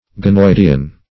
ganoidian - definition of ganoidian - synonyms, pronunciation, spelling from Free Dictionary Search Result for " ganoidian" : The Collaborative International Dictionary of English v.0.48: Ganoidian \Ga*noid"i*an\, a. & n. (Zool.)